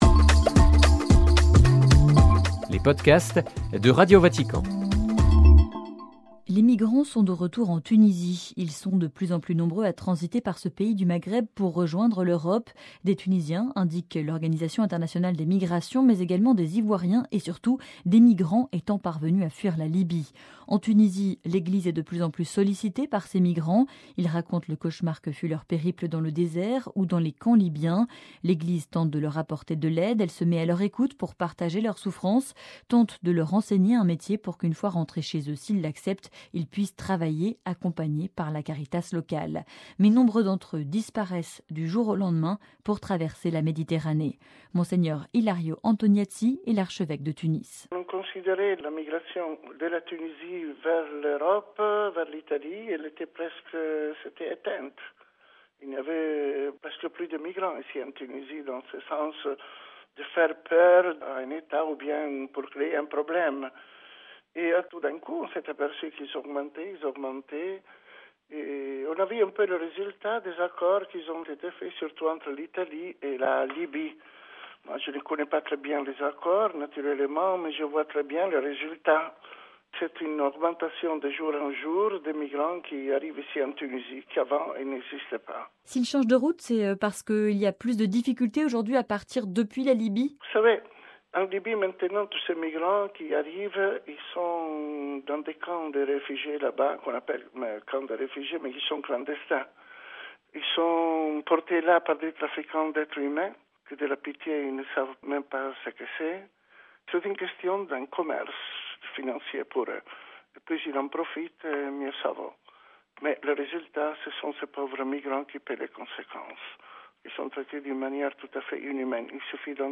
(RV) Entretien - Les migrants sont de retour en Tunisie.